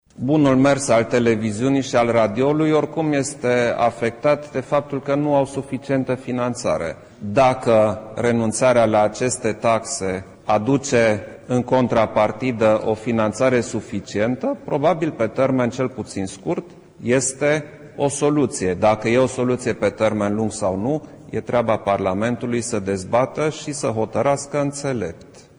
Și preşedintele României, Klaus Iohannis a vorbit despre preconizata eliminare a taxei radio tv: